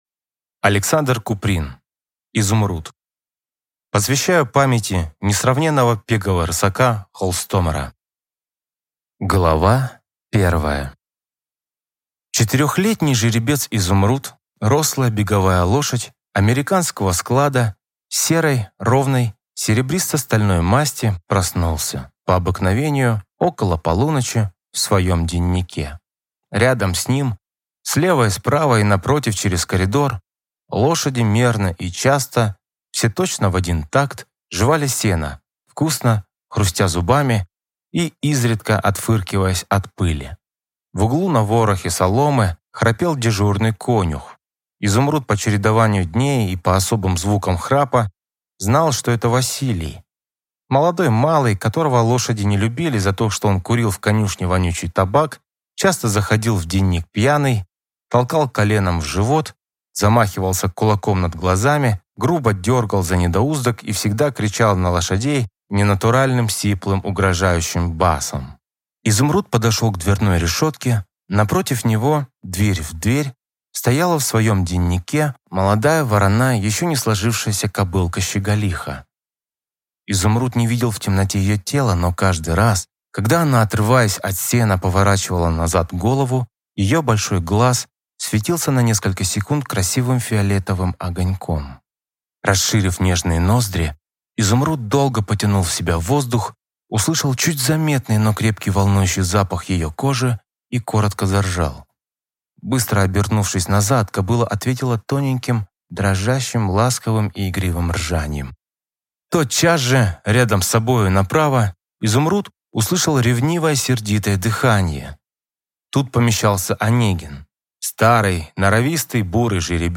Аудиокнига Изумруд